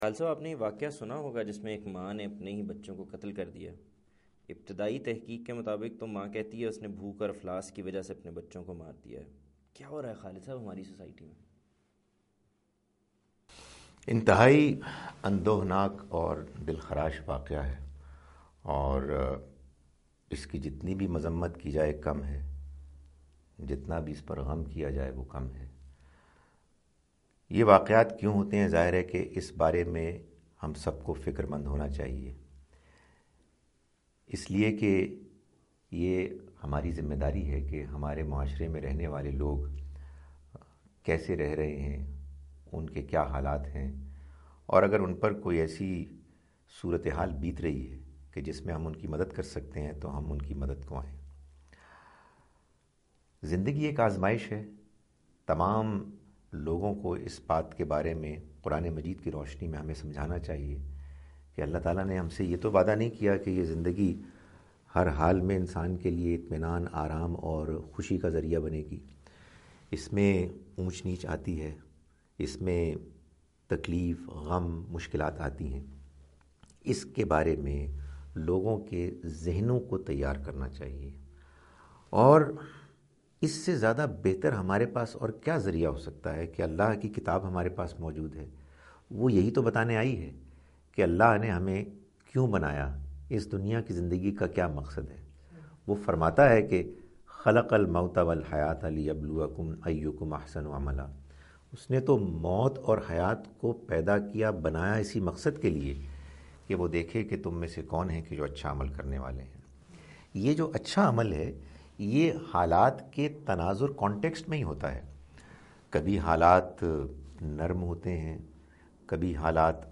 Program "Sawal Kijiye" where people ask questions and different scholars answer their questions,